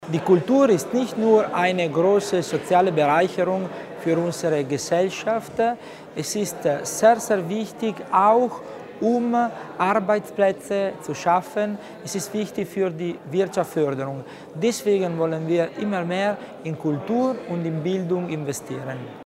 Landesrat Tommasini über die Bedeutung der Studie zur Kultur auf Landesebene